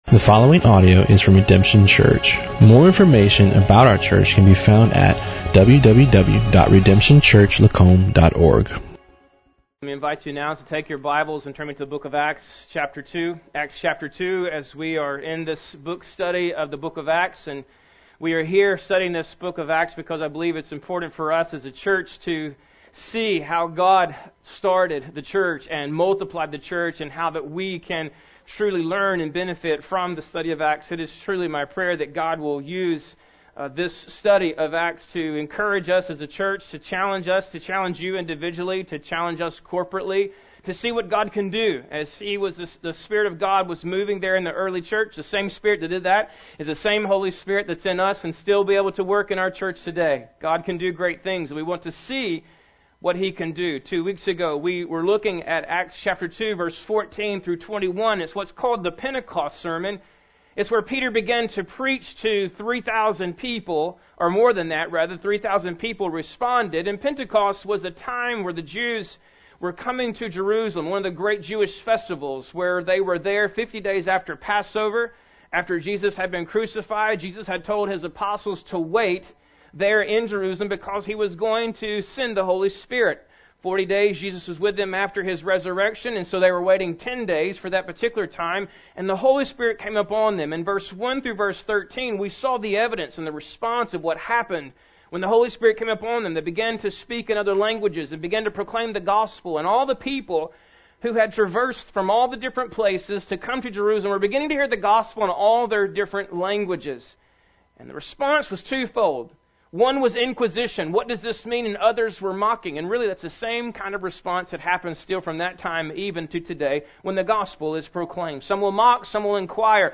"Jesus is God and Christ" Pentecost Sermon - pt. 2